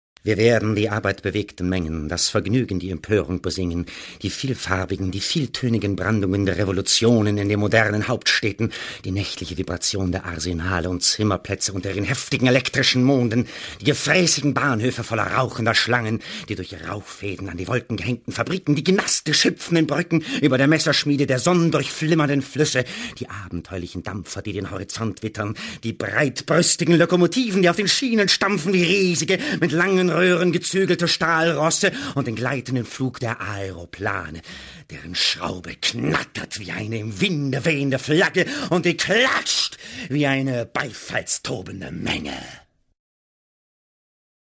warme und schĂ¶ne ErzĂ€hlerstimme, sehr variantenreich und ausdrucksstark. weiches aber klares timbre. ideal geeigent fĂŒr hĂ¶rbĂŒcher, hĂ¶rspiele , dokumentationen, voice-over und feature.
Sprechprobe: Sonstiges (Muttersprache):